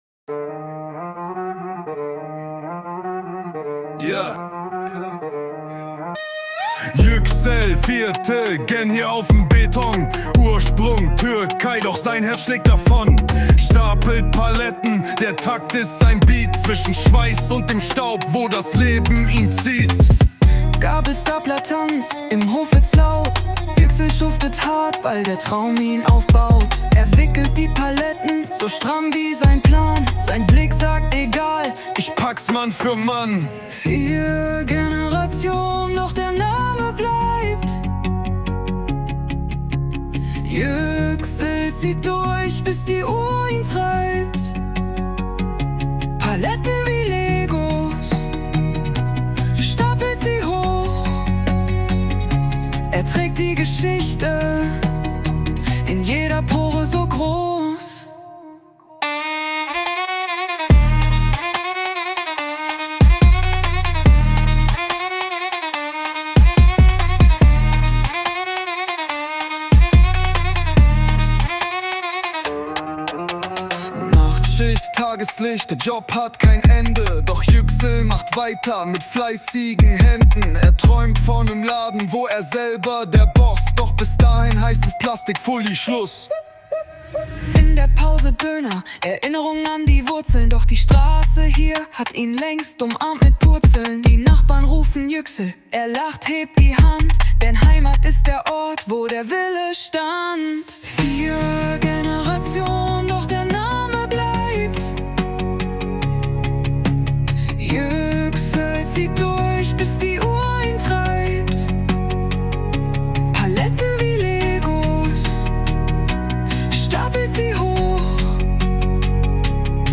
hört sich sogar richtig gut an, die weibliche Stimme erinnert etwas an Nina Chuba 🤔